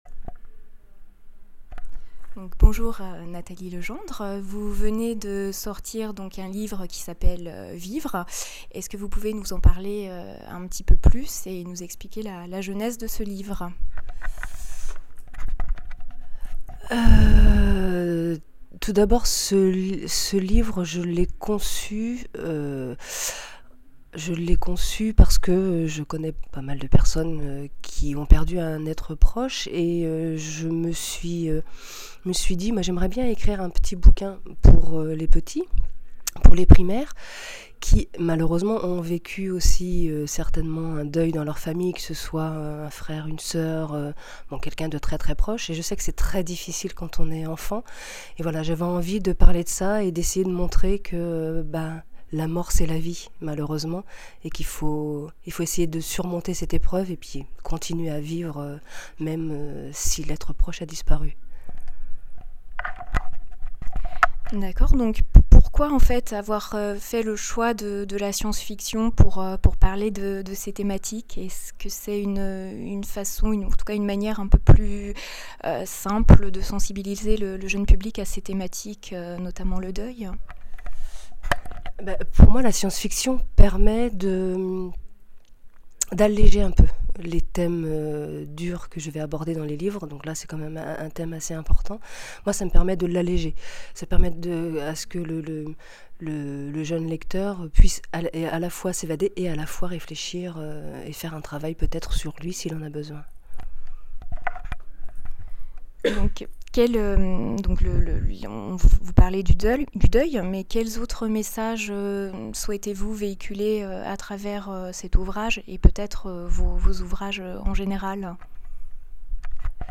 Utopiales 2011 : interview